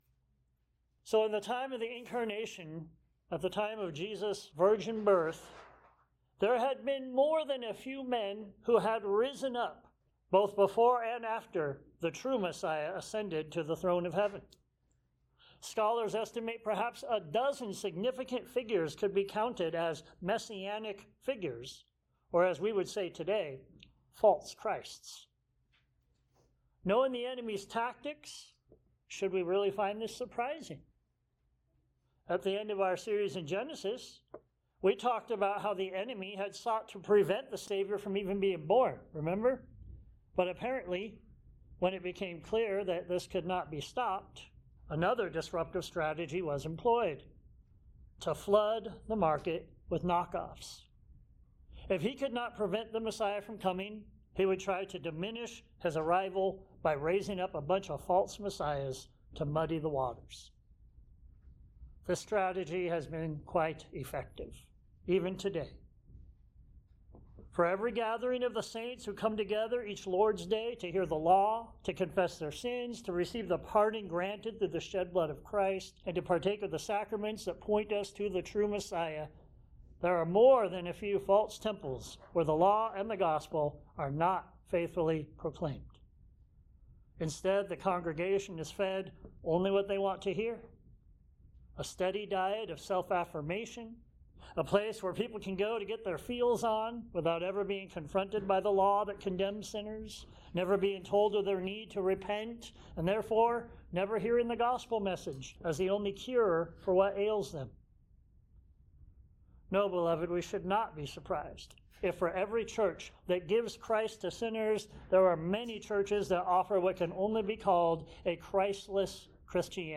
The Lamb Judges the World John 1:19-34 Sermons Share this: Share on X (Opens in new window) X Share on Facebook (Opens in new window) Facebook Like Loading...